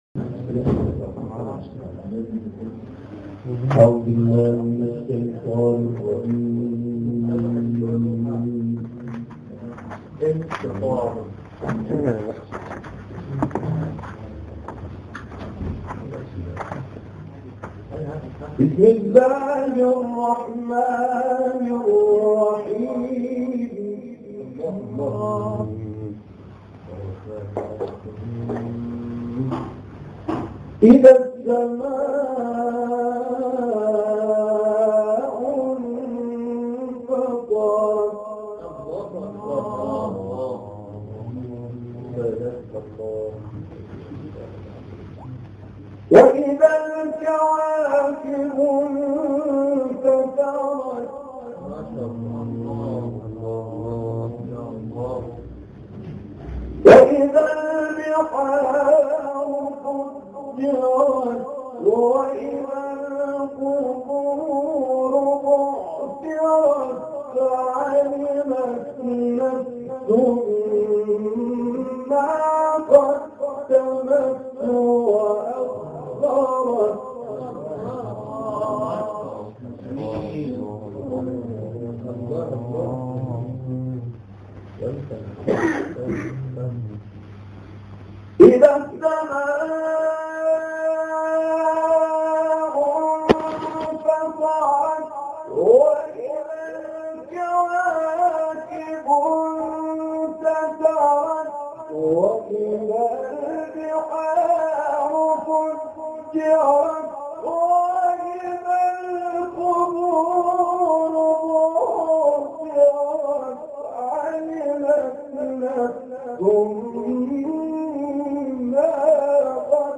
قرآن کریم – صوتی